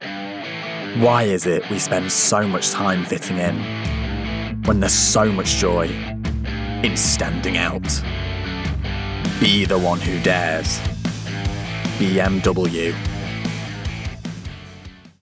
Voice Reel
BMW - Cool, Assured